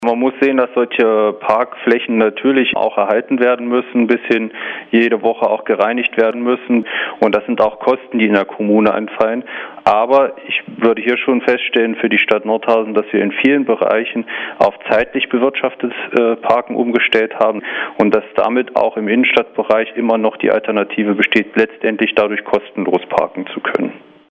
Das sagt der Bürgermeister : 01.02.2008, 13:57 Uhr
Die Stadtverwaltung Nordhausen wird vorerst nicht dem Parkgebührenmodell von Bad Salzungen folgen. Grund: die Stadt ist auf die Parkgebühren angewiesen, so Bürgermeister Matthias Jendricke (SPD) im Landeswelle Gespräch.